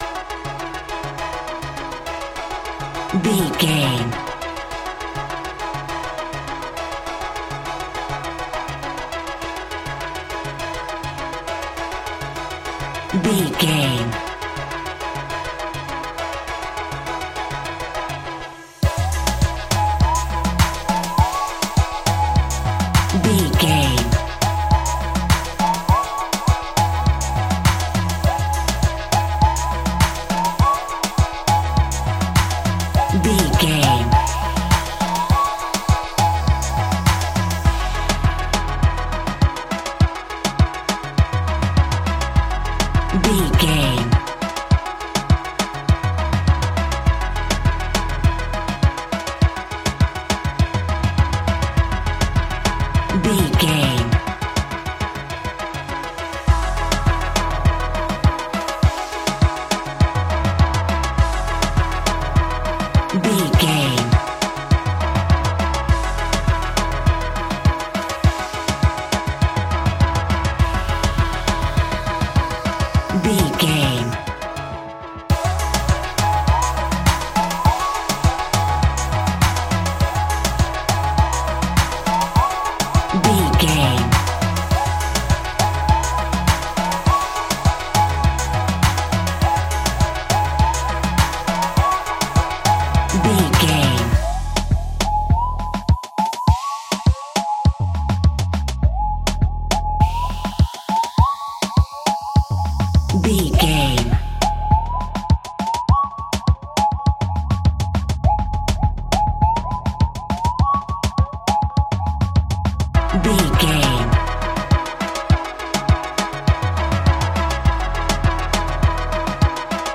Aeolian/Minor
C#
reggae instrumentals
laid back
chilled
off beat
drums
skank guitar
hammond organ
percussion
horns